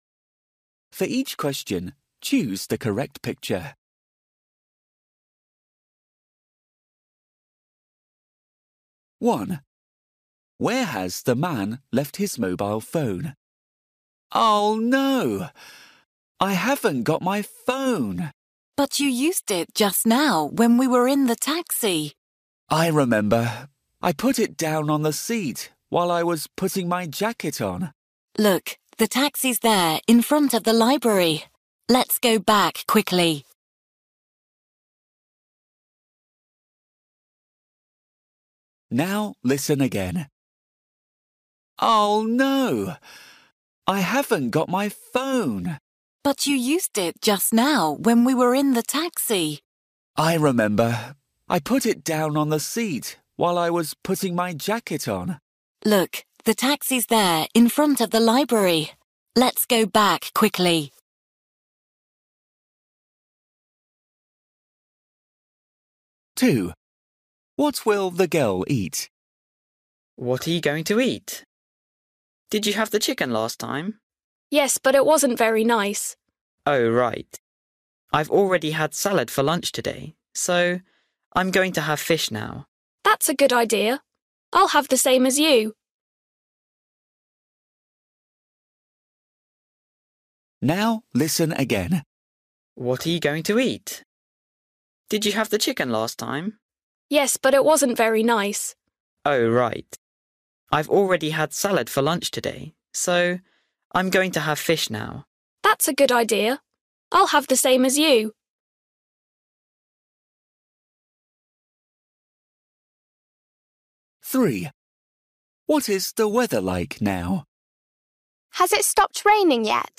Listening: Conversations at home or in a shop